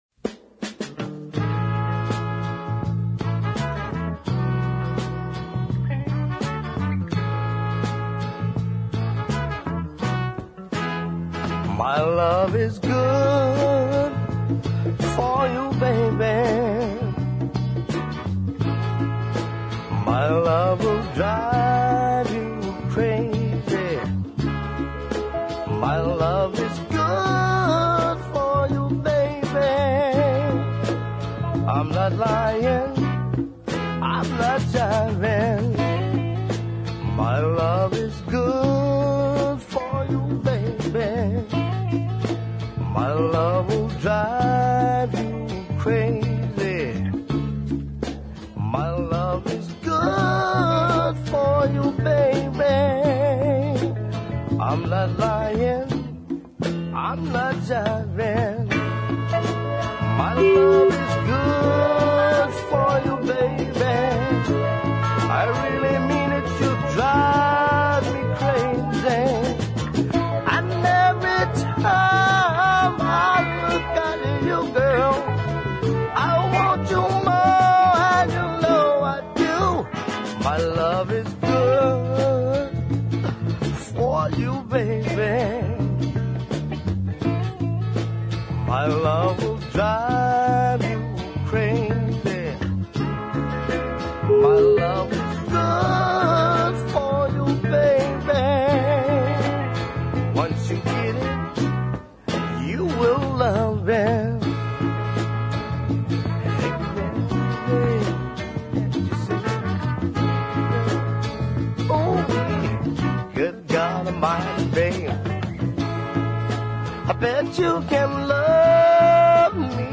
very listenable downtempo offering
so these are New York sides.